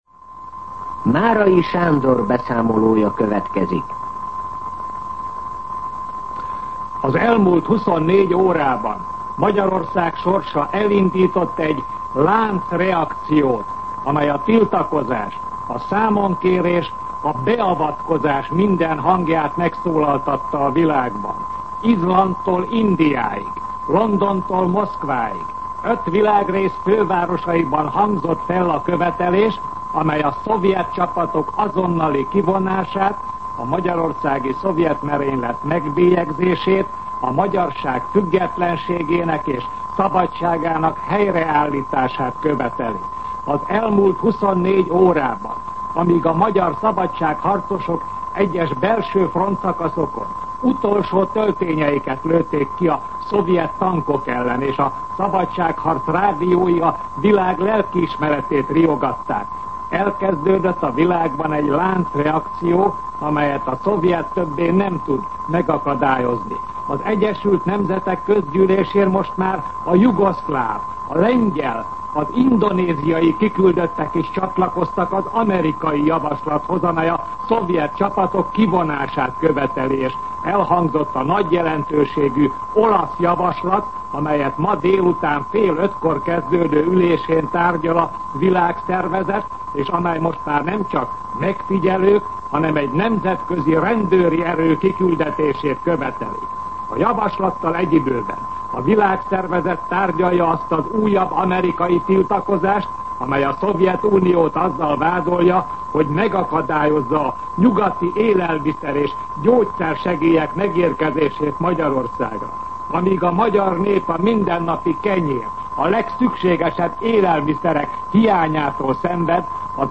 MűsorkategóriaTudósítás